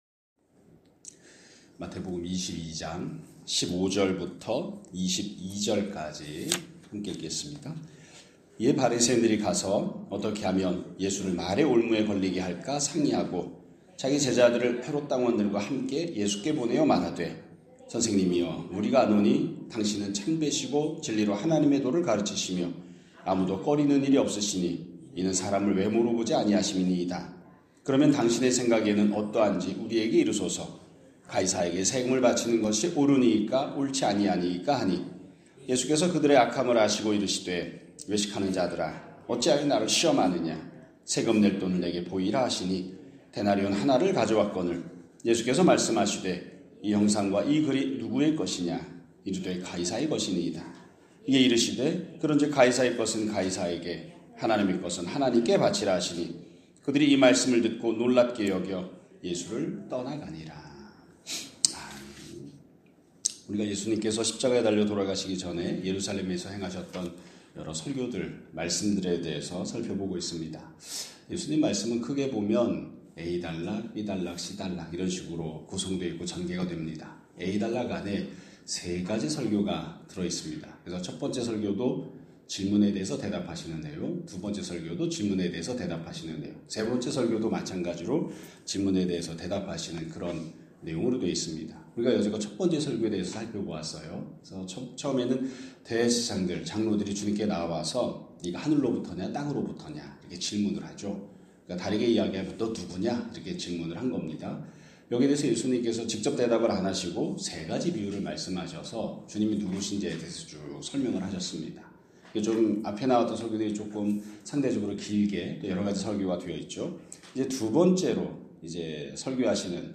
2026년 2월 10일 (화요일) <아침예배> 설교입니다.